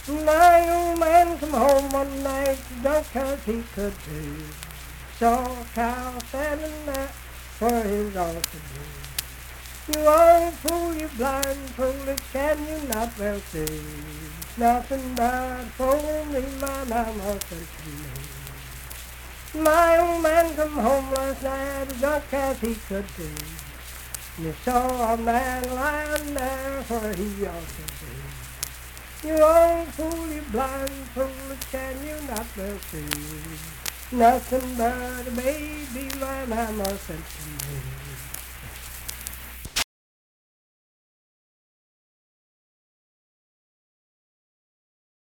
Unaccompanied vocal music
Verse-refrain 4(4w/R).
Performed in Ivydale, Clay County, WV.
Voice (sung)